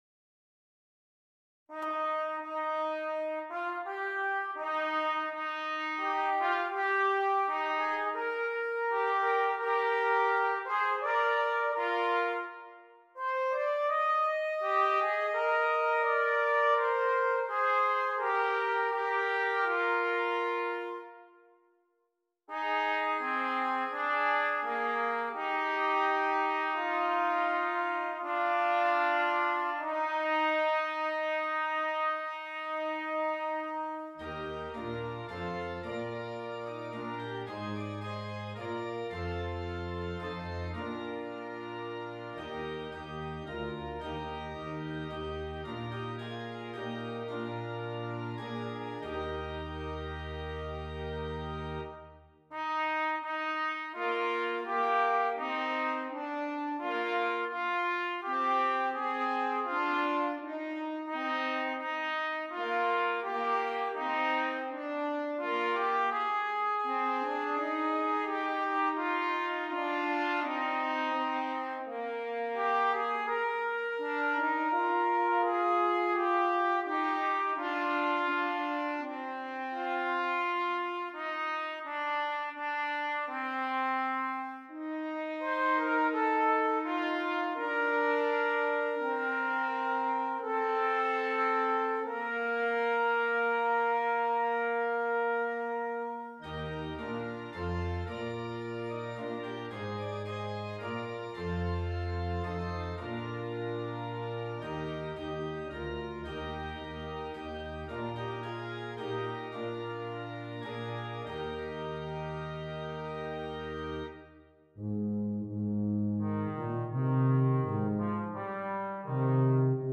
Brass Quintet and Organ